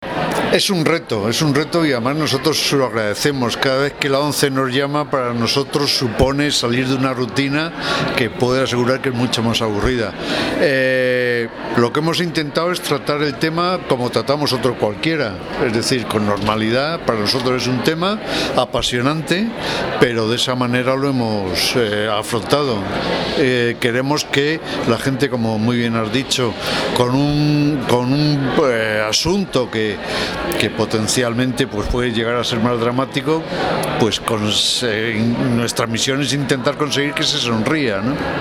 Julio Rey explicó al respecto a 'Así Somos' que,